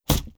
Close Combat Attack Sound 15.wav